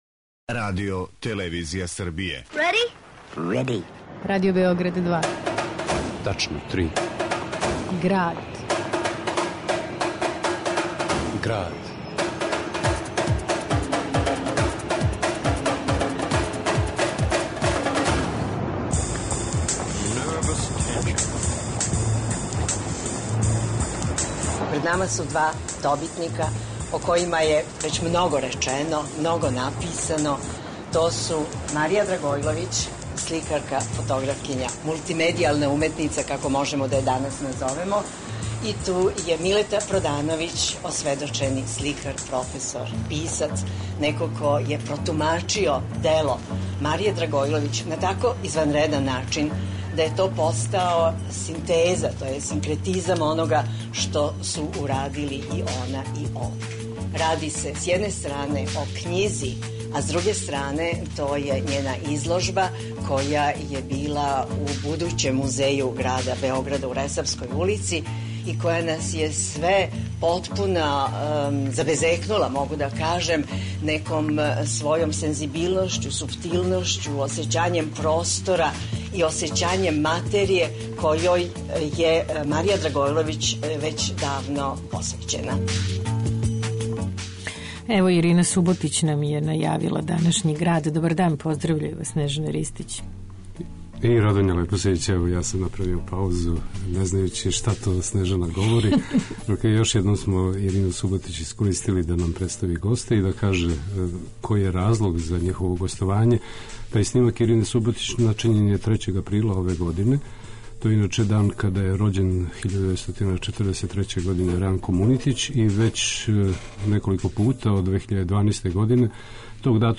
уз архивски снимак